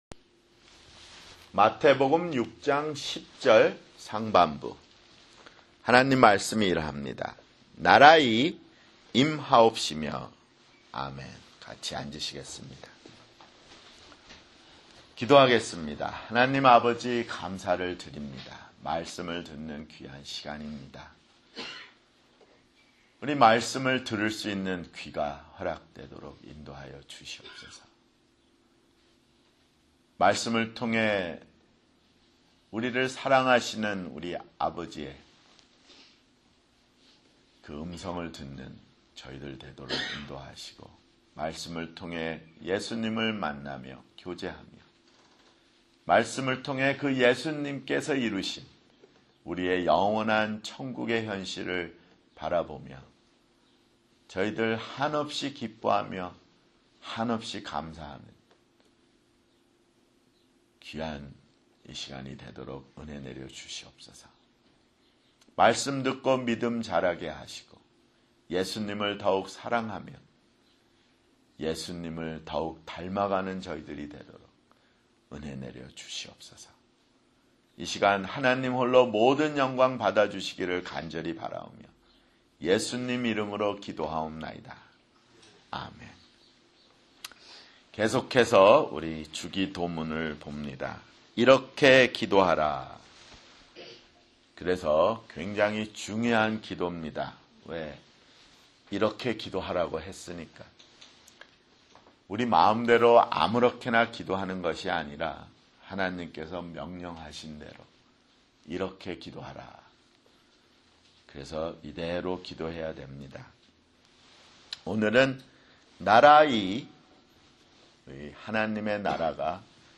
[주일설교] 주기도문 (3)